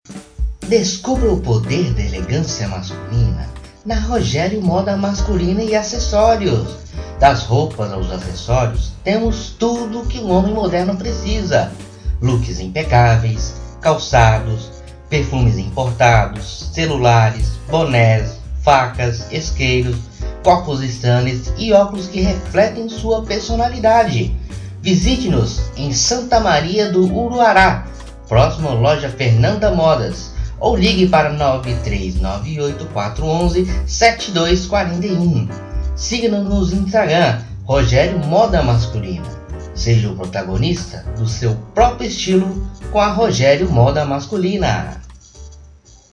PROPAGANDA PARA COMERCIANTES